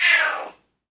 Cat Meow
描述：A cat meowing in surprise.
标签： cat animal feline meowing meow
声道立体声